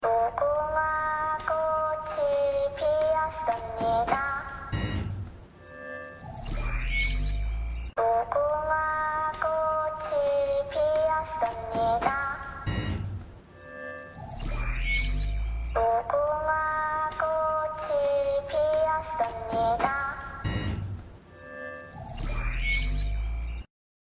• Качество: высокое
Звук куклы из Игры в кальмара